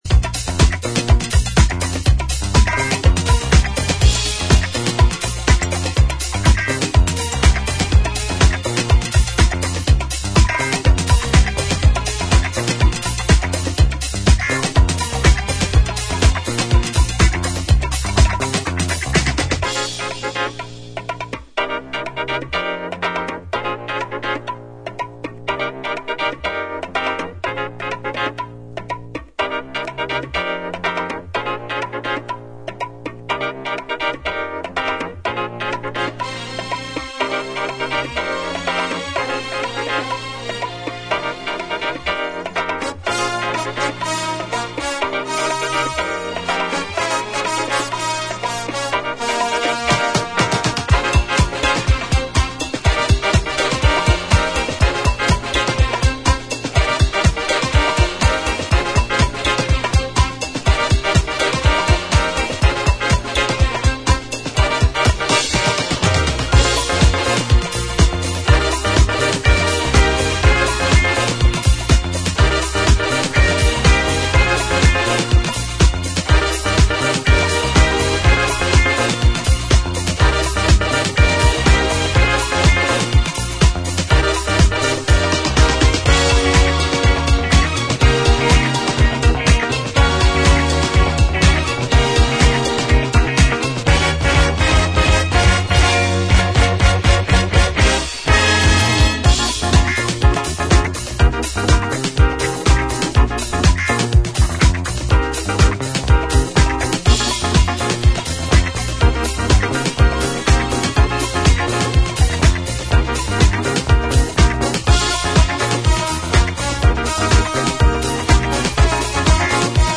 ジャンル(スタイル) HOUSE / DISCO / RE-EDIT